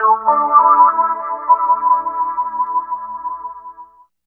29 GUIT 2 -L.wav